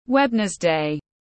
Thứ 4 tiếng anh gọi là webnesday, phiên âm tiếng anh đọc là /ˈwenz.deɪ/
Webnesday /ˈwenz.deɪ/